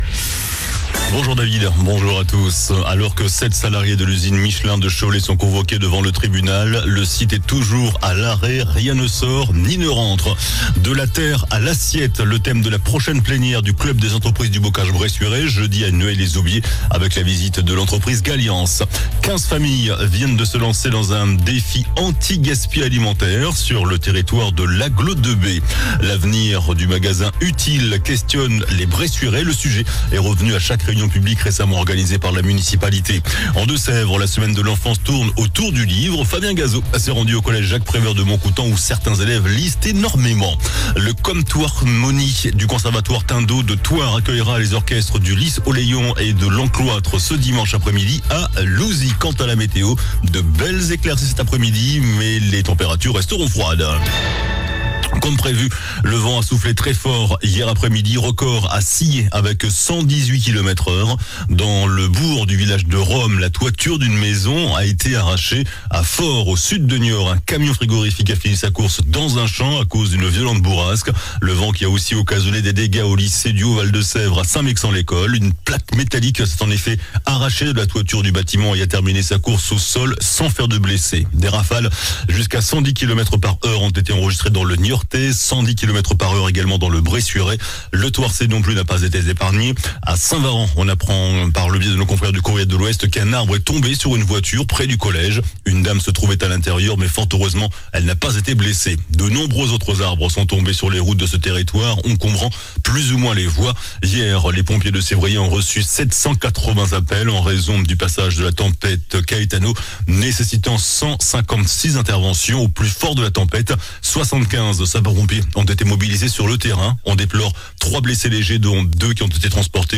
JOURNAL DU VENDREDI 22 NOVEMBRE ( MIDI )